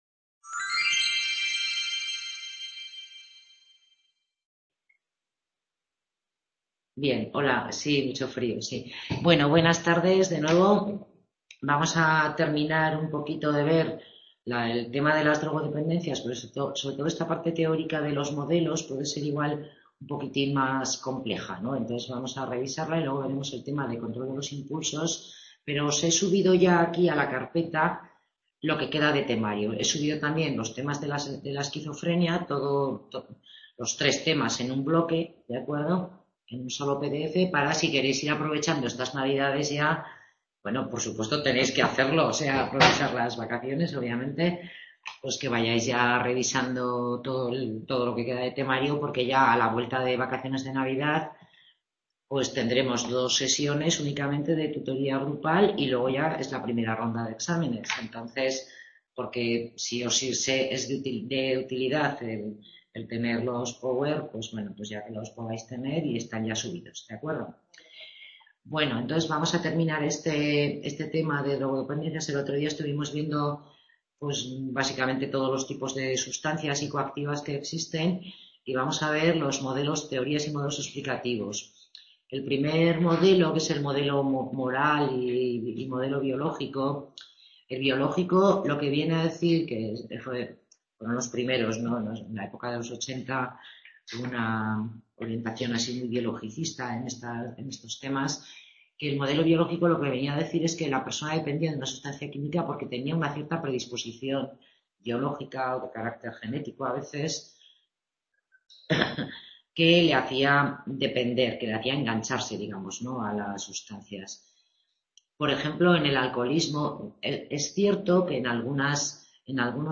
Tutoría grupal dedicada al tema del Juego Patológico